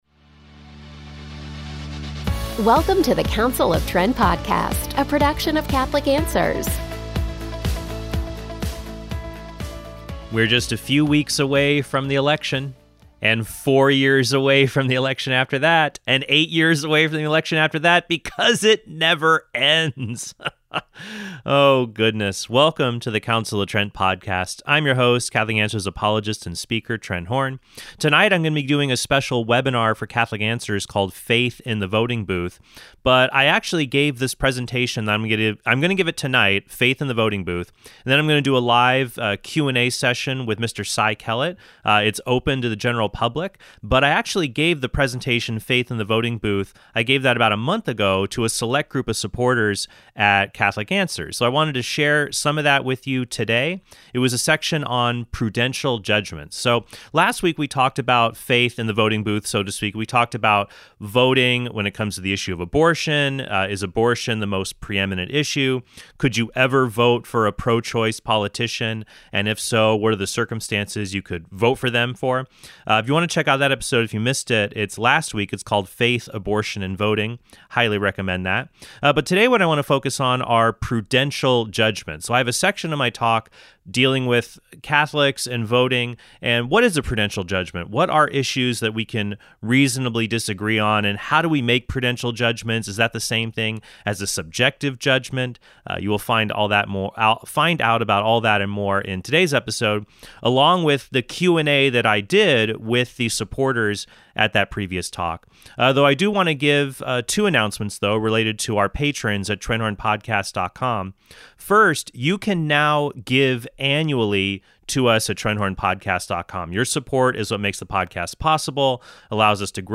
He also answers questions on voting submitted by supporters of Catholic Answers.